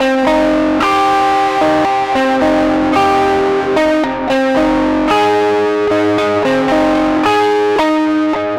Track 15 - Guitar 08.wav